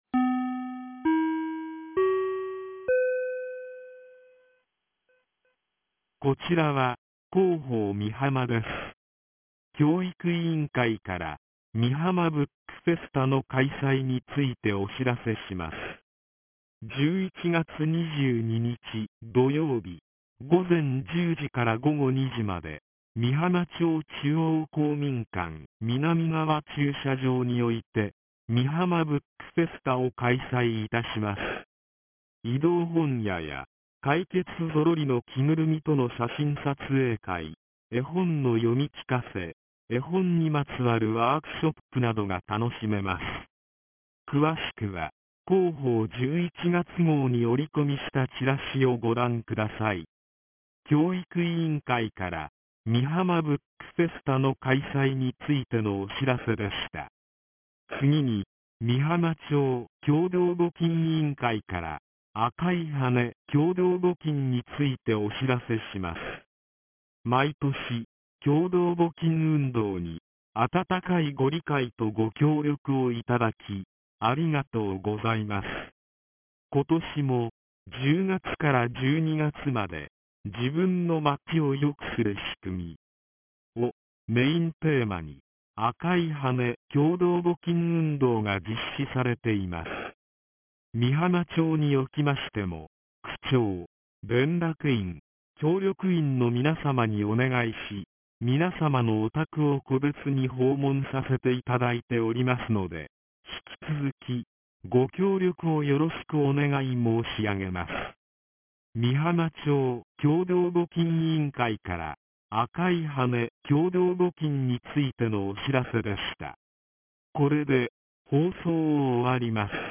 ■防災行政無線情報■
放送音声